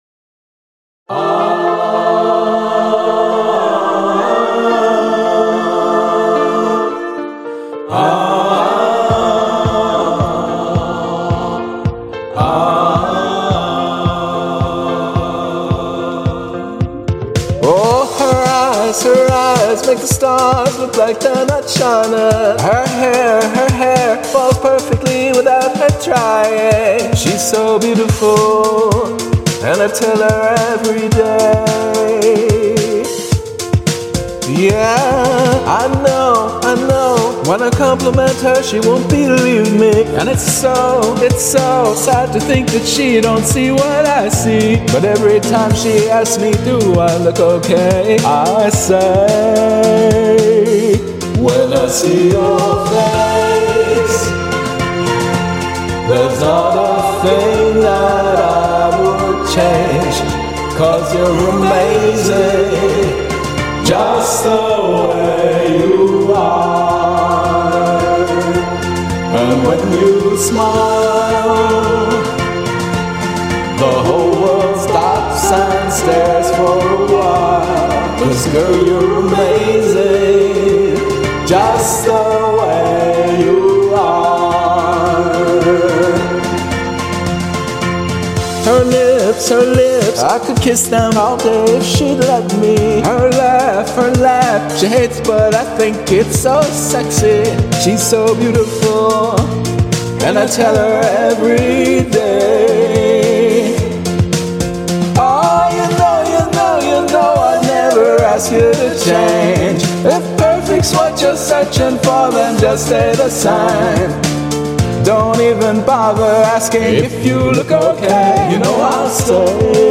vocals
F    Dm    B♭    F